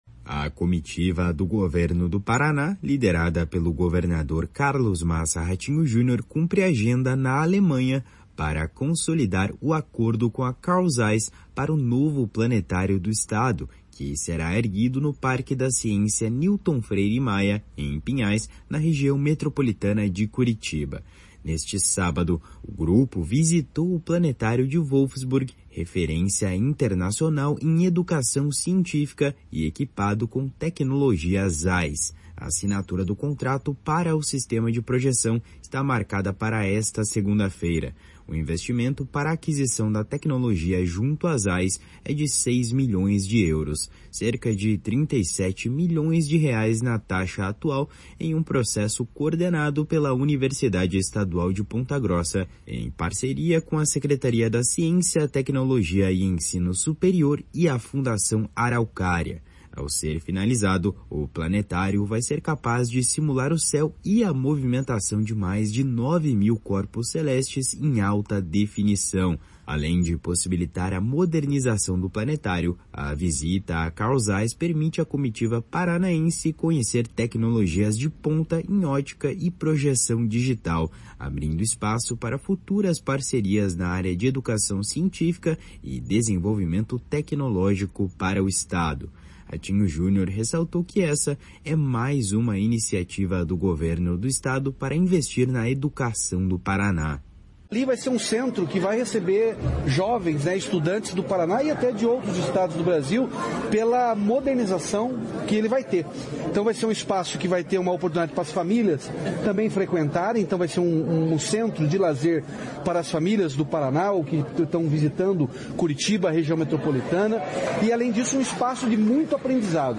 // SONORA RATINHO JUNIOR //
// SONORA ALDO BONA //